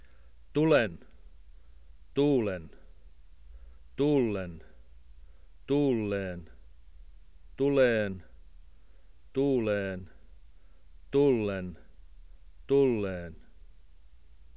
7.7: finsk [ˈtulen ˈtu:len ˈtu:l:en ˈtu:l:e:n ˈtule:n ˈtu:le:n ˈtul:en ˈtul:e:n]